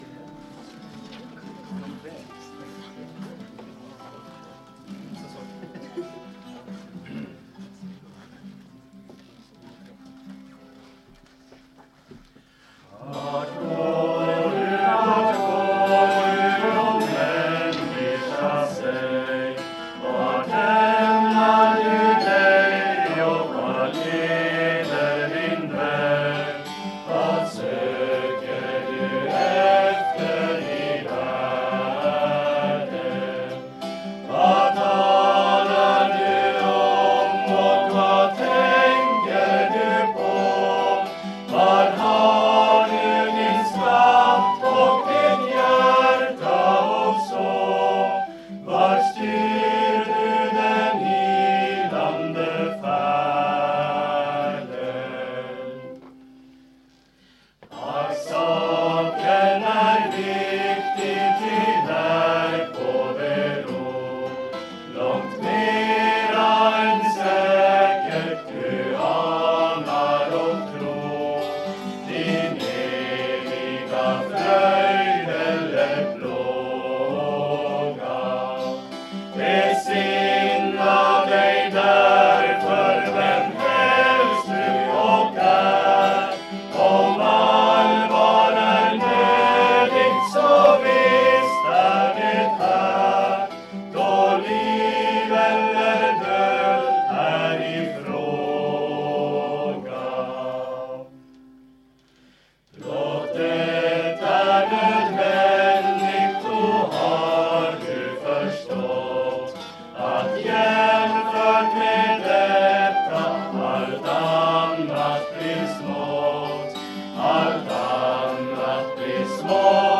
Lyssna till körsång
Körsånger från Midsommarläger 2025, Ytterstfors Kursgård.
Korsanger-Midsommar-2025.mp3